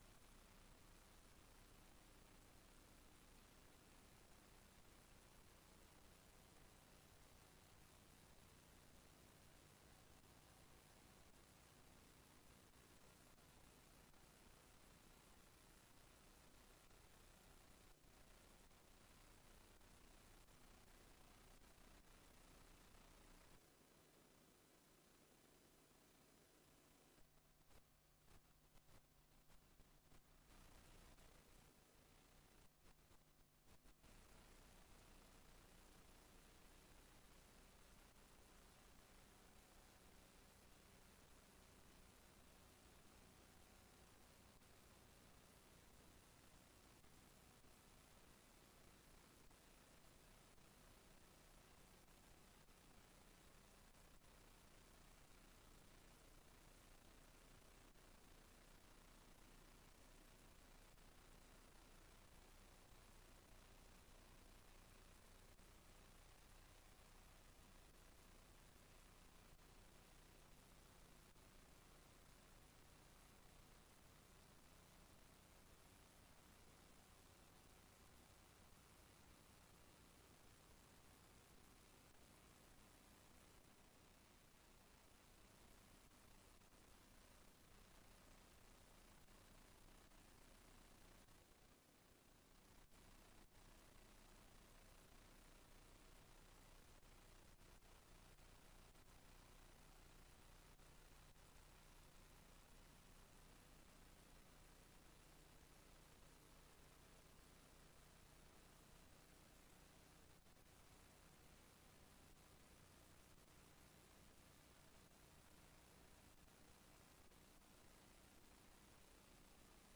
Raadsbijeenkomst 10 september 2025 19:30:00, Gemeente Tynaarlo